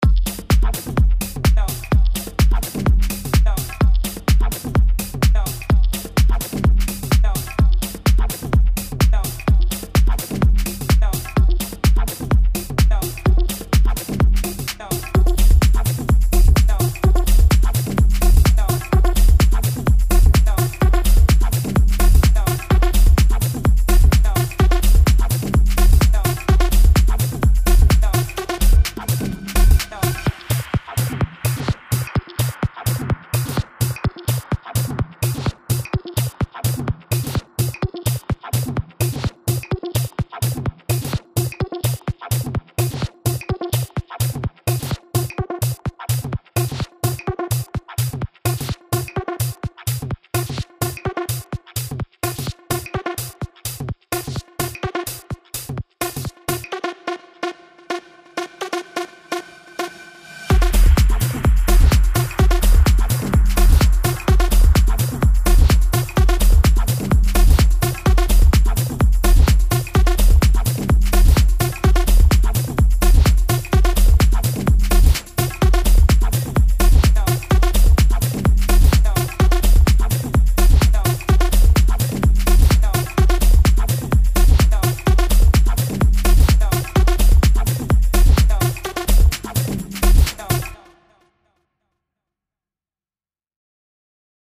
100 Kick Drum loops in rex2. and 24bit wav. formats,normalized and tempo synced at 128bpm.
100 Percussion loops in rex2. and 24bit wav. formats,normalized and tempo synced at 128bpm.
DRUM KIT ONE SHOTS